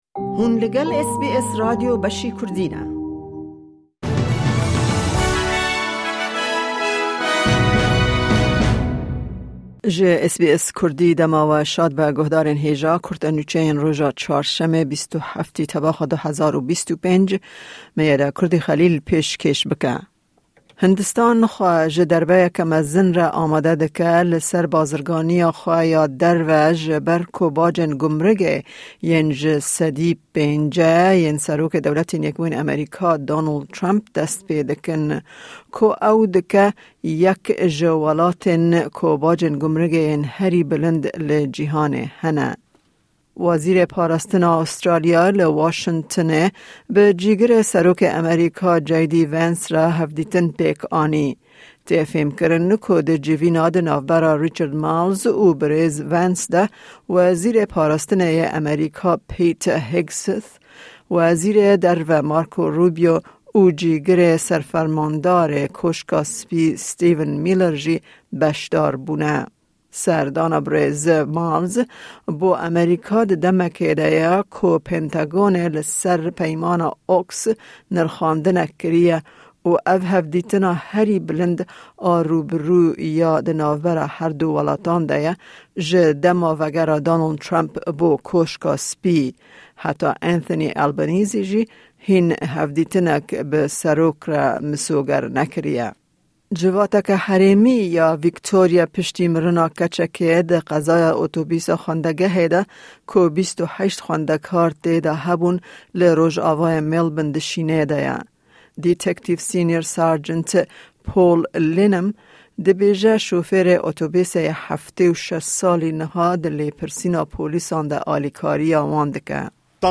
Kurte Nûçeyên roja Çarşemê, 27î Tebaxa 2025